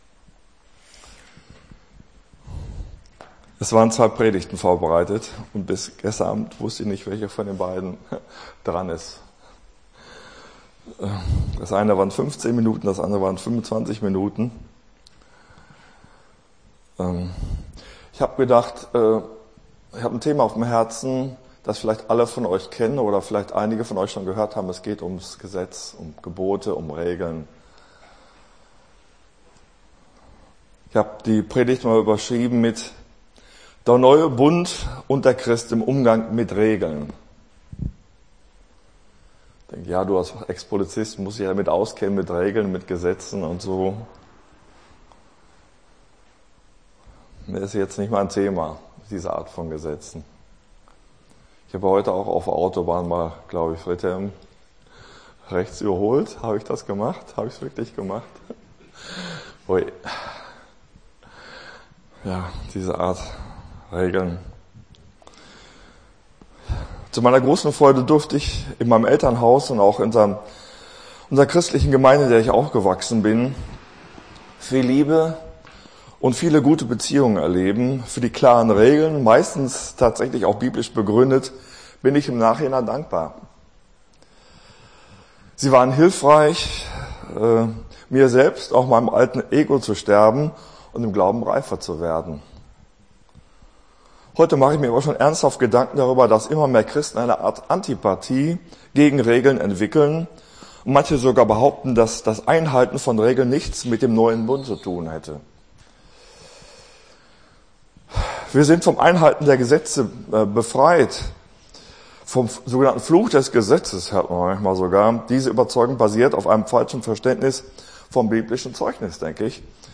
Gottesdienst 04.08.24 - FCG Hagen